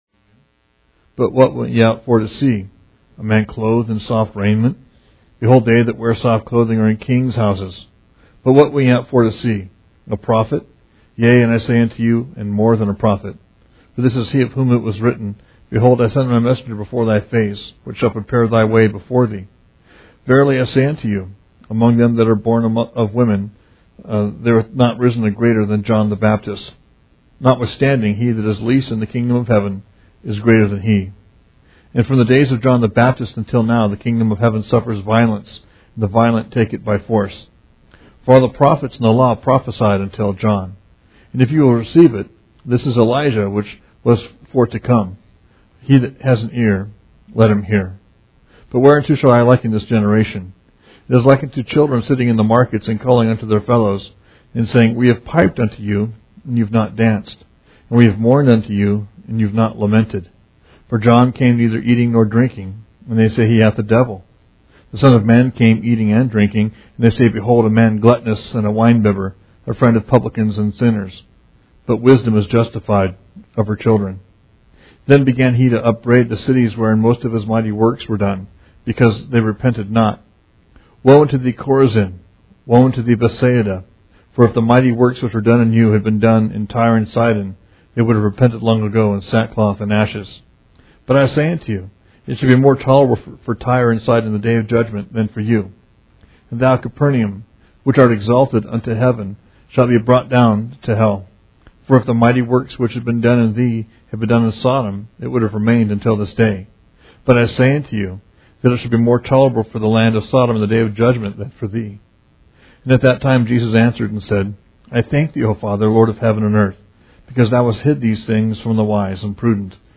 Verse By Verse